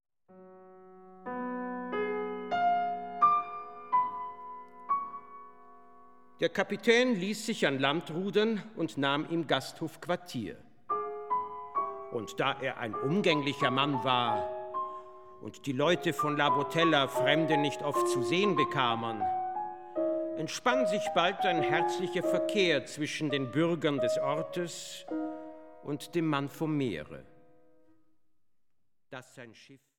Klavier
Werke für Sprecher und Klavier.